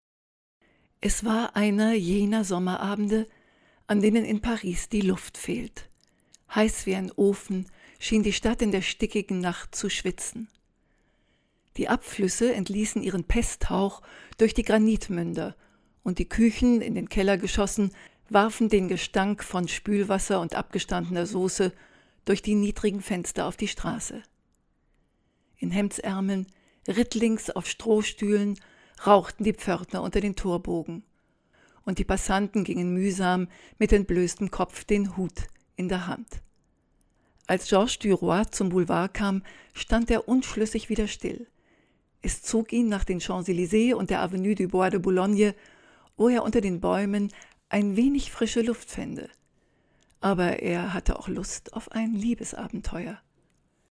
professionelle deutsche Sprecherin.
Sprechprobe: Industrie (Muttersprache):
german female voice over artist